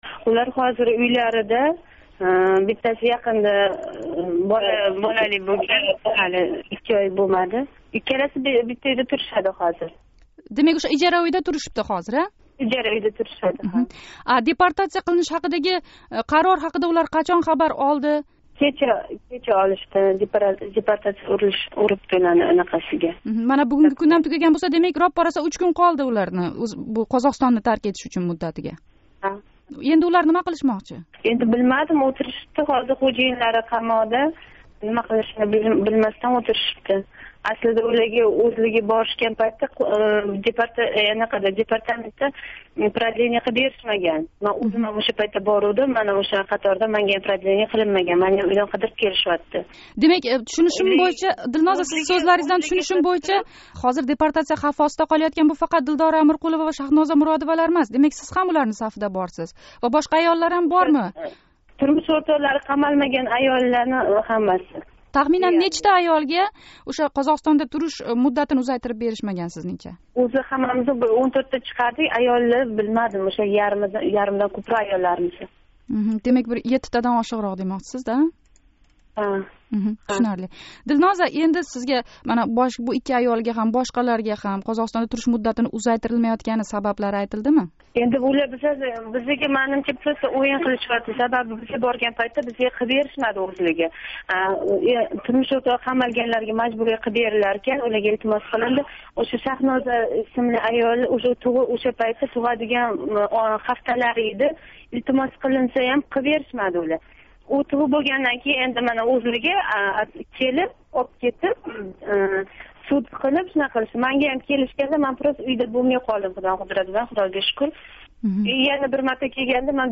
Олмаотадаги икки қочқин билан суҳбат